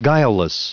Prononciation du mot guileless en anglais (fichier audio)
Prononciation du mot : guileless